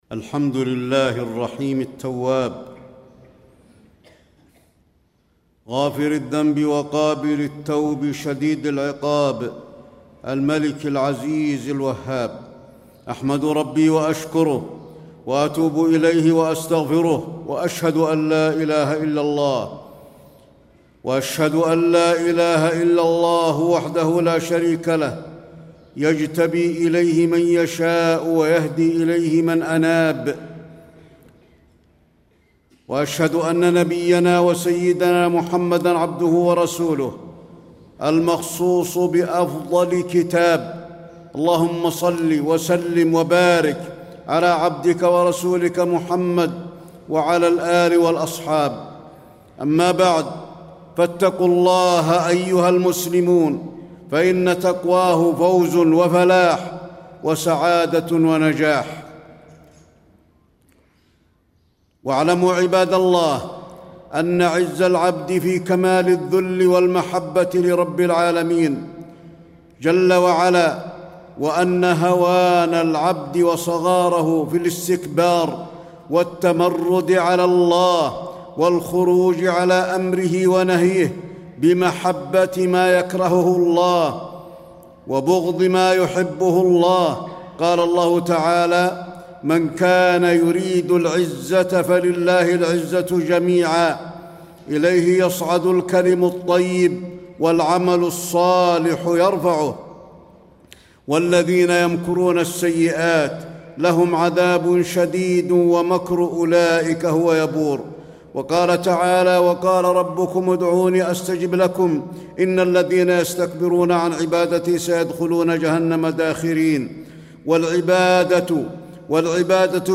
تاريخ النشر ٢ ربيع الأول ١٤٣٥ هـ المكان: المسجد النبوي الشيخ: فضيلة الشيخ د. علي بن عبدالرحمن الحذيفي فضيلة الشيخ د. علي بن عبدالرحمن الحذيفي التوبة وفضلها